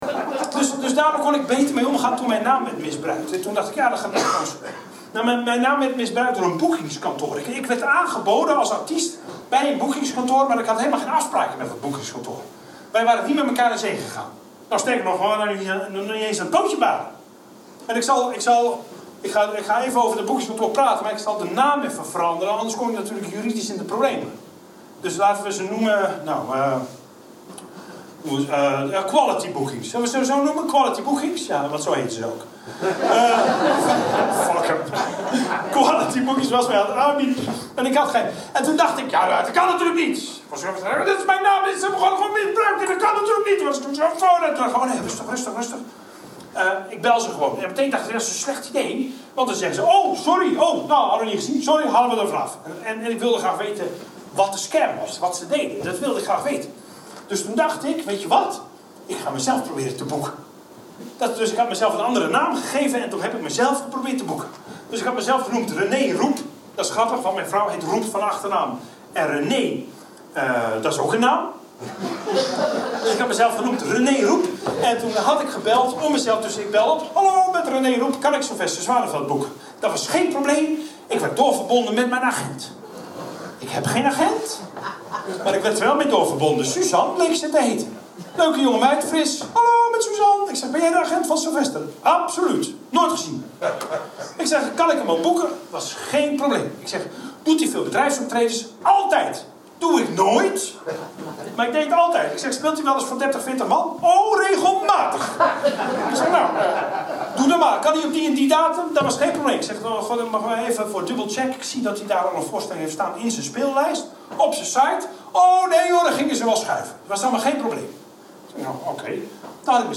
luisterboek
ThemaComedy en stand-up